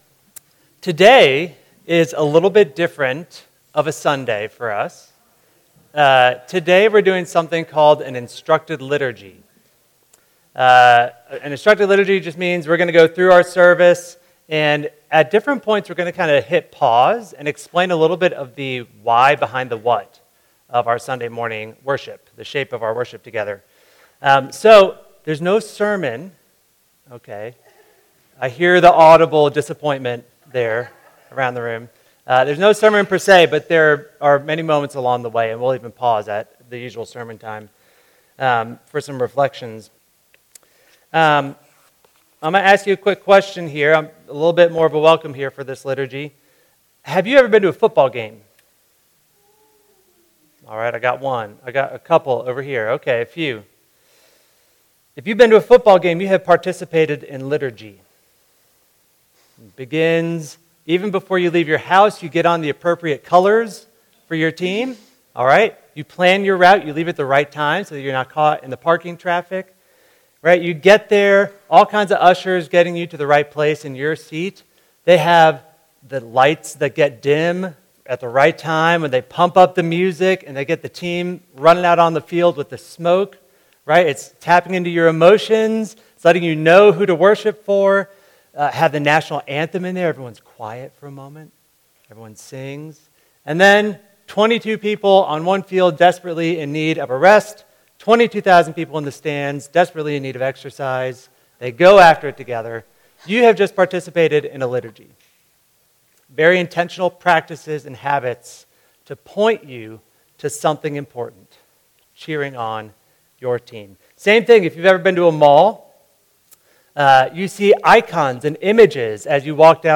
"...therefore I am." (Instructed Liturgy)